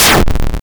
boom4.wav